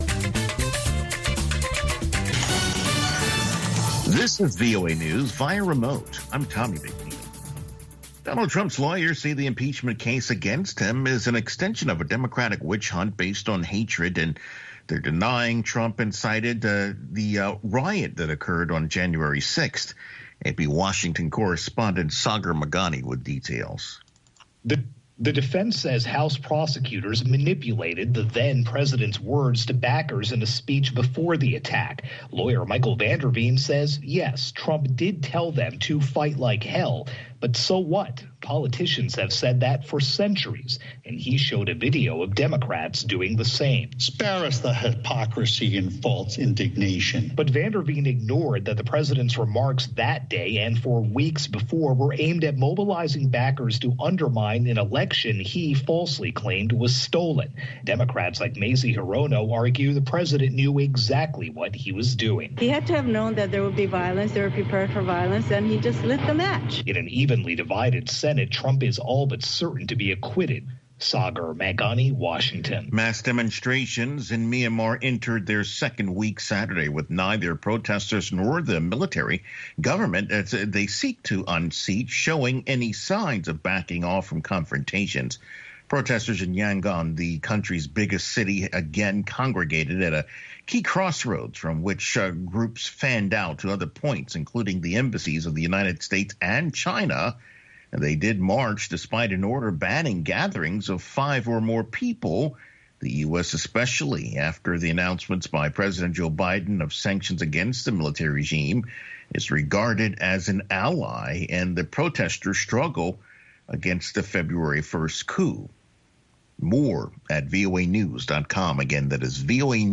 exclusive interviews